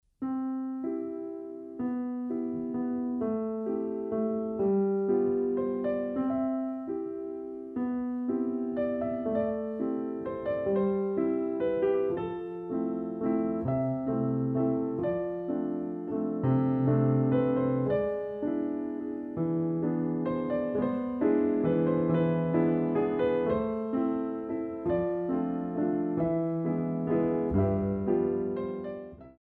Compositions for Ballet Class
Ronds de jambe à terre plus long